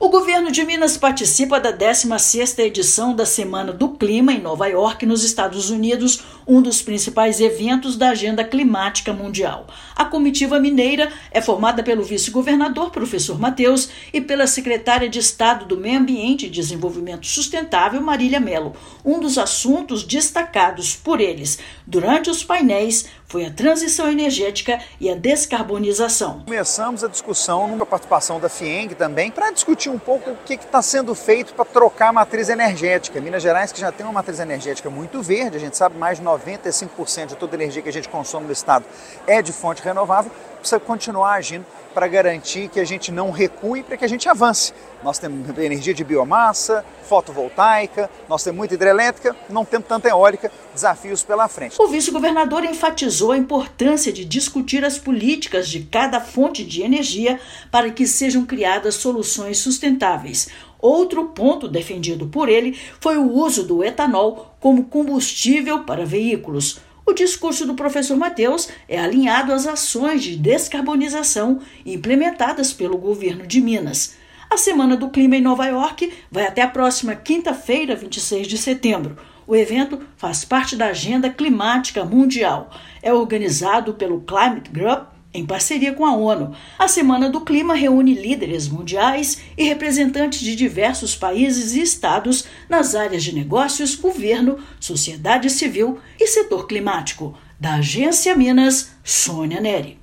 Comitiva destacou importância do etanol e citou ações para reduzir emissões no mercado do aço. Ouça matéria de rádio.